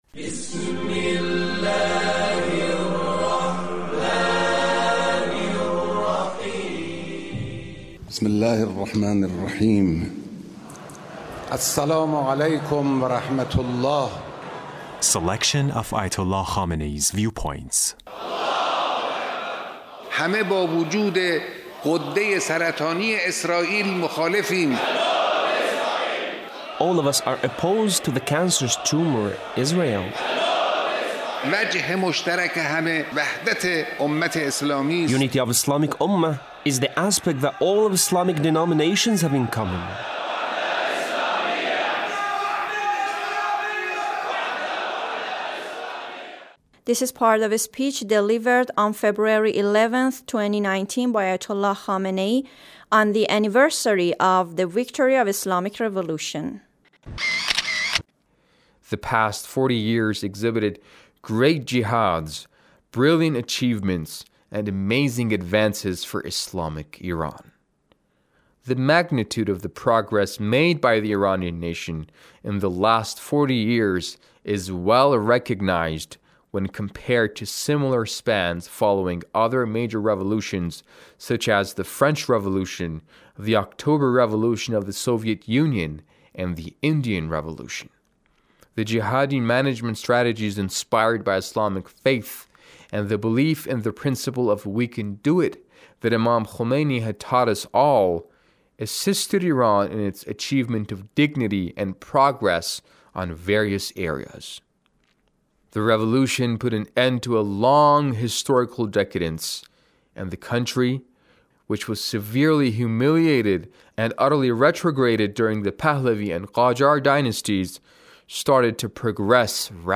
Leader's Speech (17)